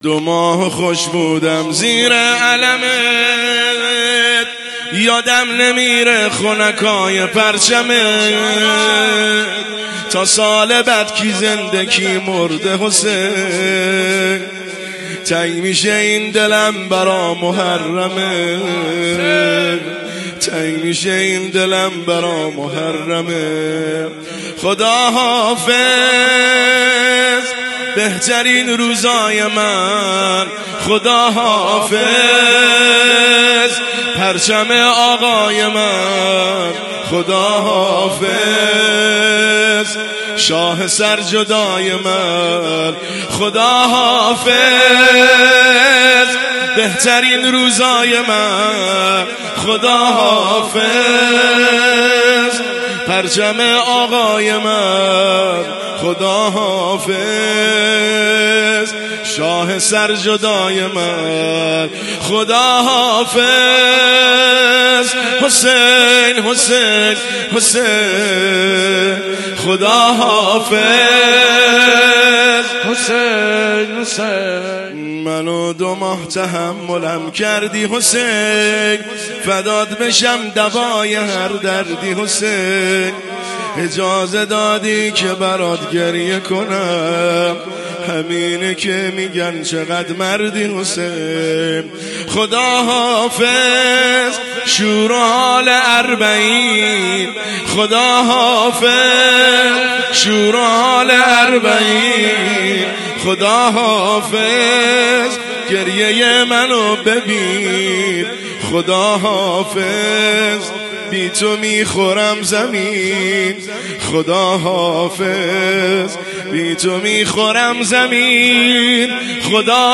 زمینه  شام 30 صفرالمظفر 1403